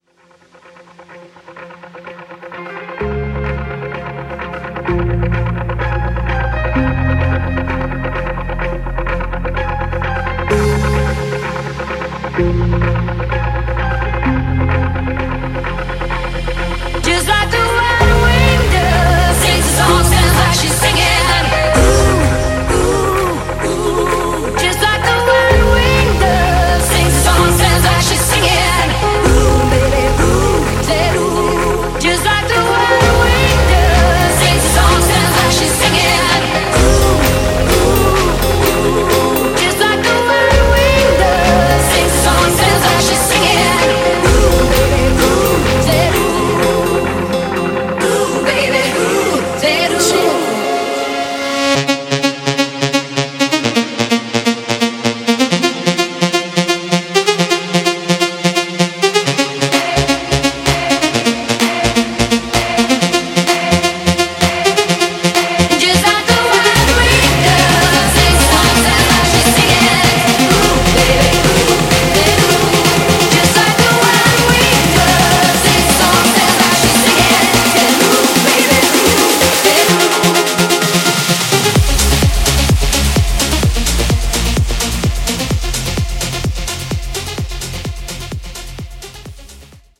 Genre: HIPHOP